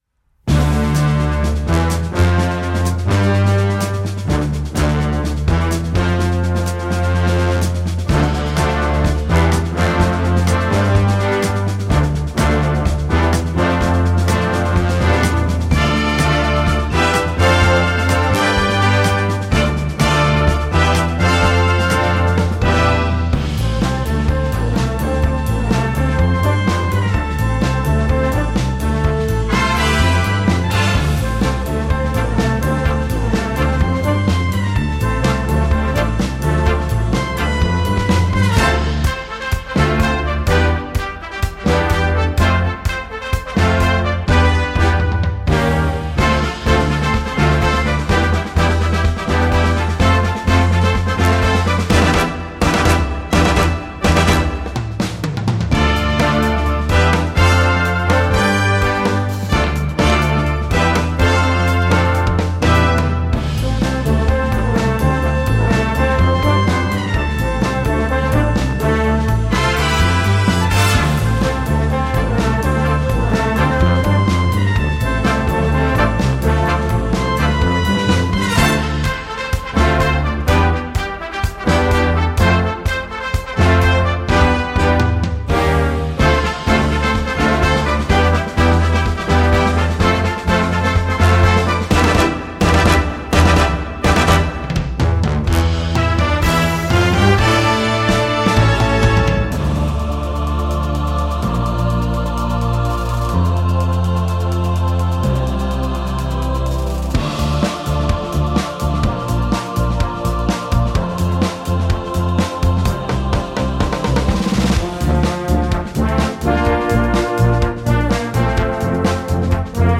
emotionales und zugleich festliches Werk
Besetzung: Concert Band